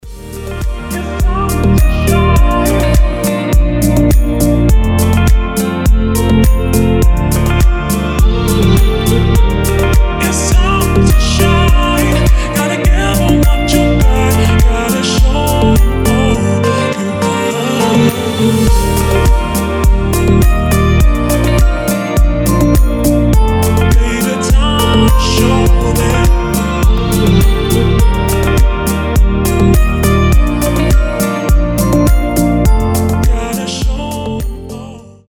танцевальные
deep house , мелодичные , tropical house , chillout